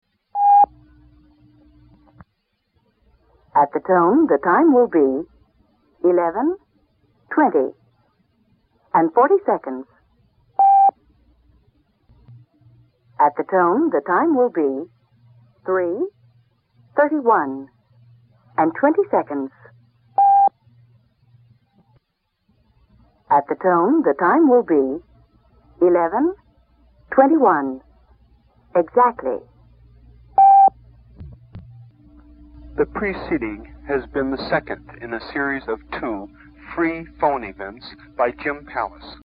The Phoneyvent would be cued up on an audio cassette tape player.
They stayed until the signature at the end.